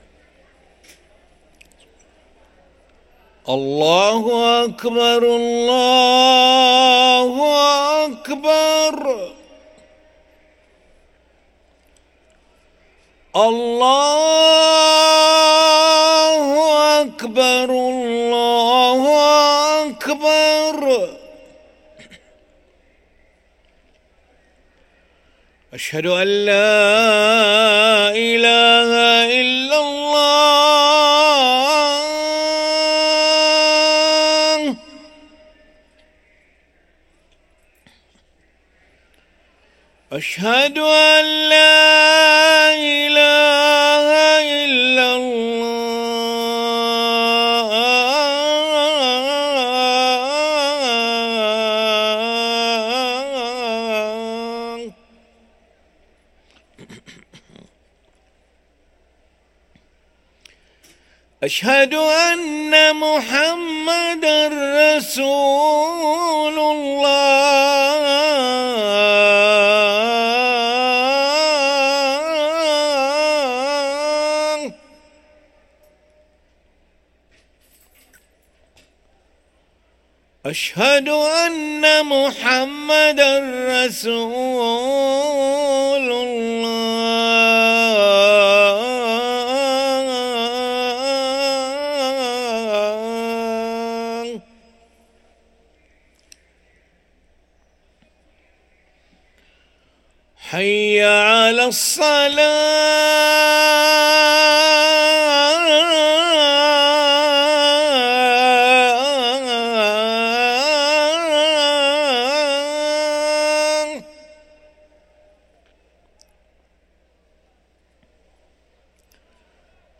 أذان العشاء للمؤذن علي ملا الأحد 19 جمادى الأولى 1445هـ > ١٤٤٥ 🕋 > ركن الأذان 🕋 > المزيد - تلاوات الحرمين